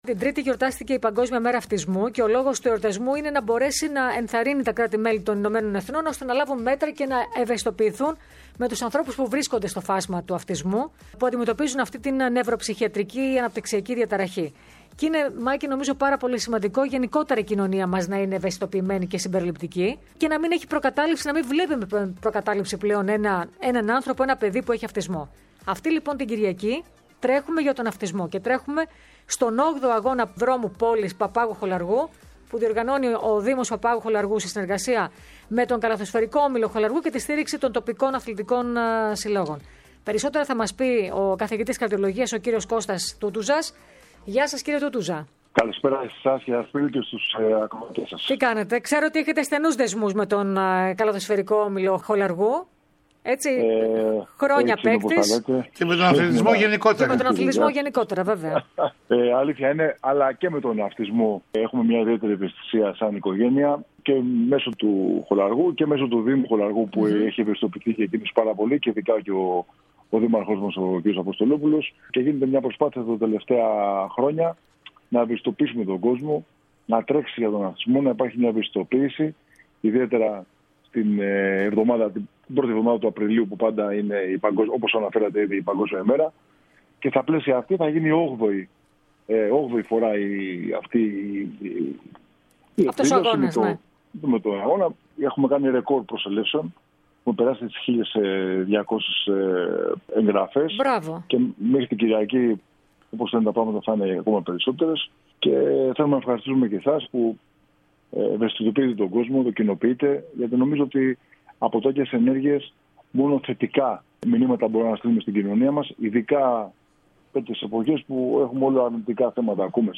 Ο καθηγητής καρδιολογίας μίλησε στην εκπομπή CITIUS ALTIUS FORTIUS